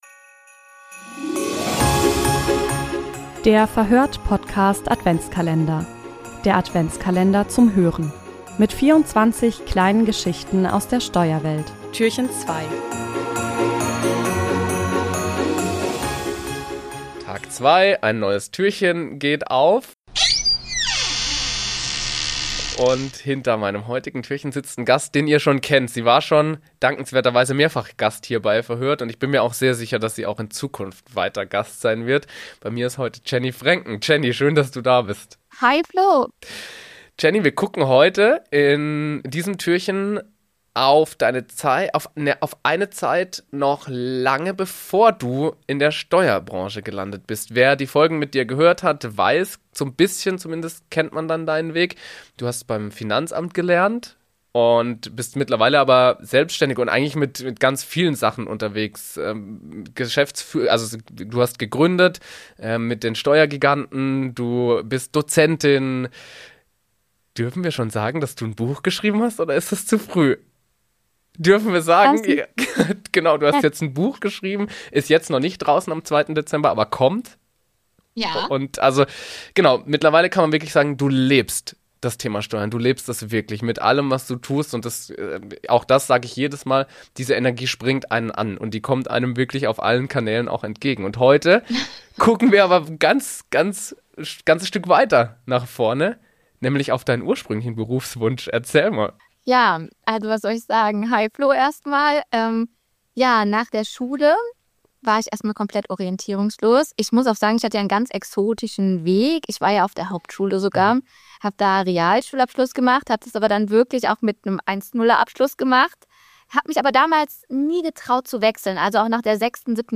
Im Verhör(t) Podcast-Adventskalender erzählen Steuerexpertinnen und Steuerexperten Geschichten und Anekdoten aus ihrem Alltag.